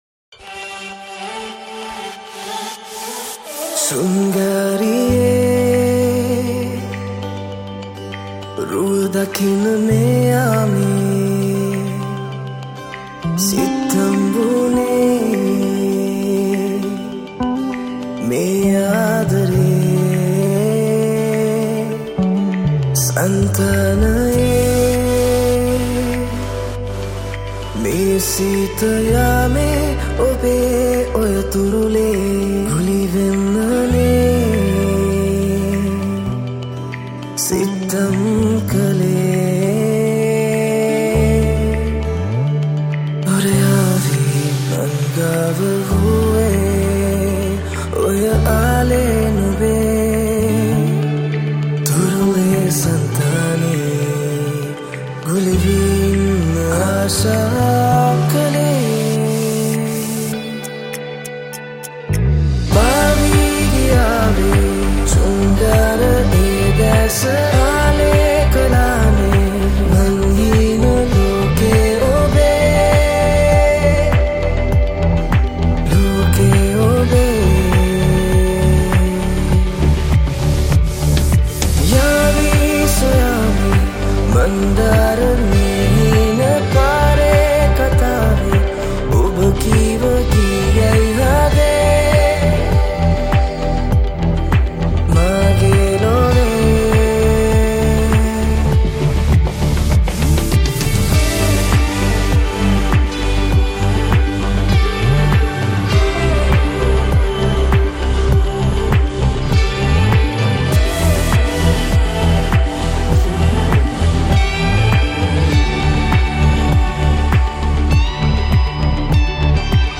Lead Guitar, Bass, Rhythm